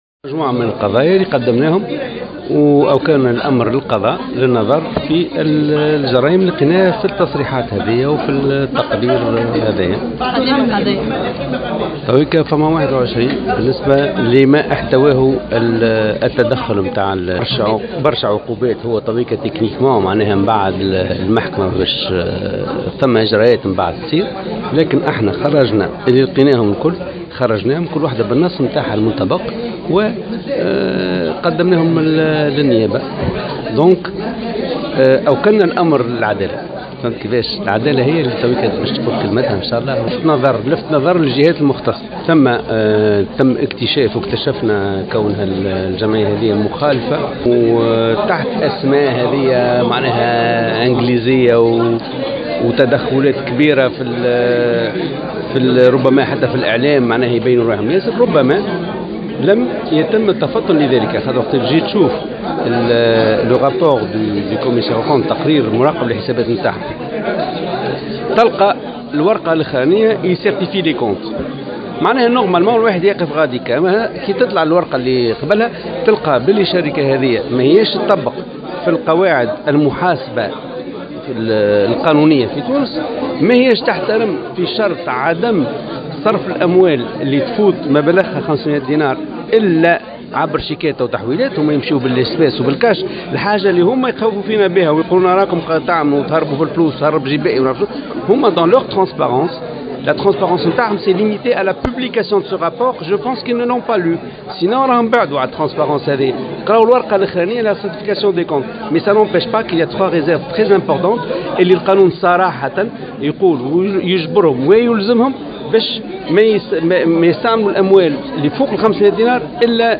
خلال ندوة صحفية انعقدت بمقر المجمع بالعاصمة